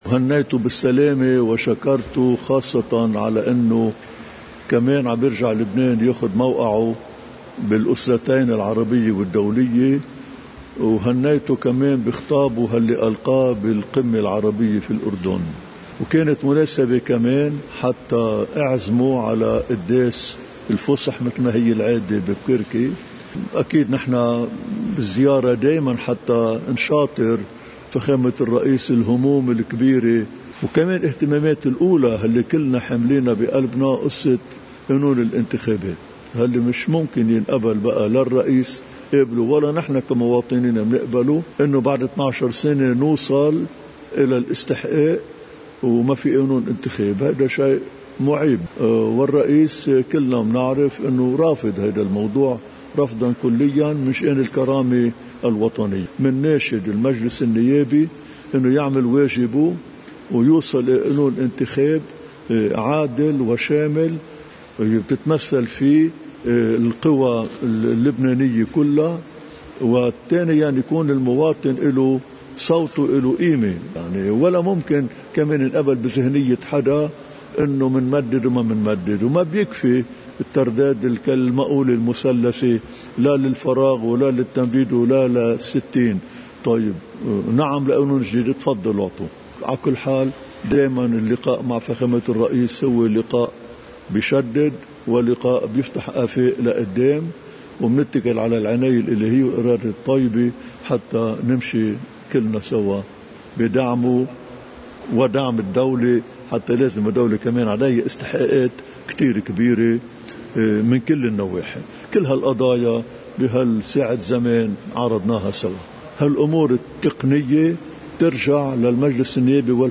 مقتطف من حديث البطريرك الراعي إثر لقائه رئيس الجمهورية العماد ميشال عون في بعبدا:
رداً على سؤال عن موقفه الأخير من حزب الله وتدخله، قال الراعي: